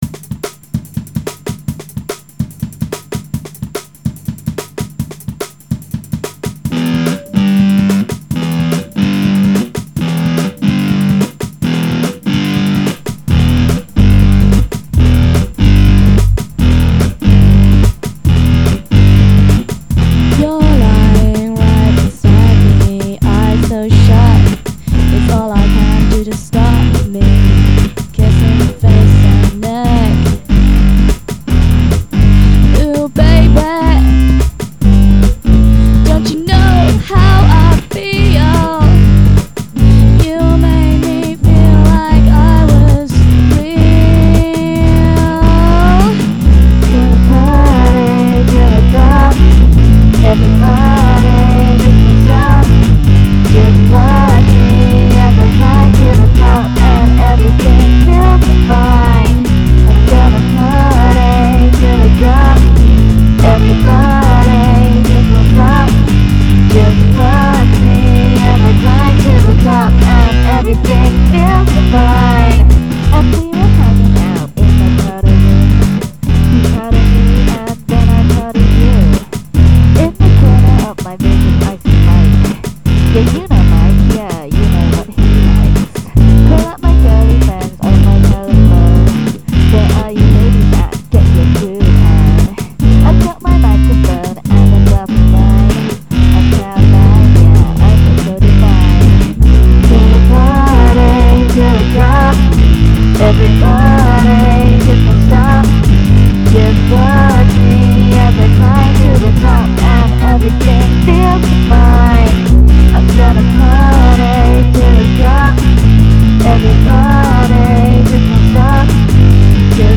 dance/electronic
Techno
Disco
Eighties/synthpop